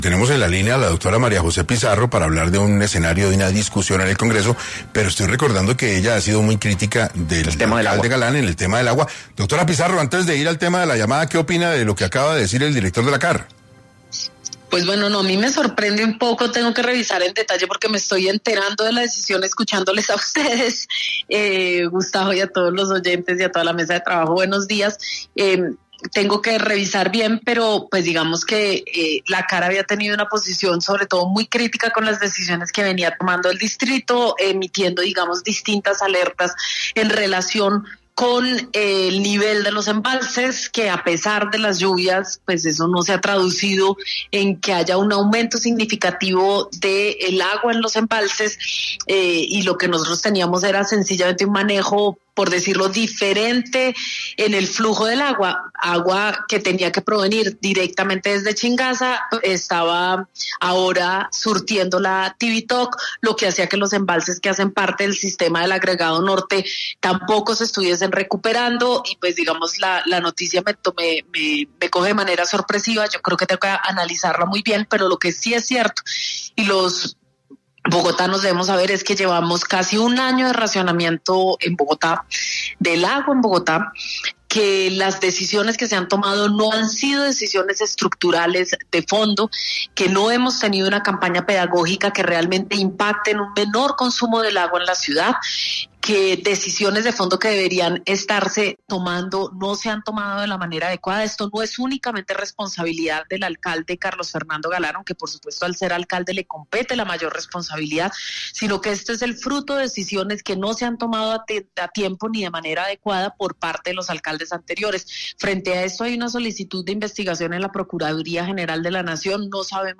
La senadora, María José Pizarro, estuvo en 6AM para responder a los señalamientos en su contra por parte de Jota Pe Hernández y qué acción judicial instaurará para frenar los ataques.
Ante esta coyuntura, Pizarro pasó por los micrófonos de 6AM, para exponer de manera más detallada su punto de vista.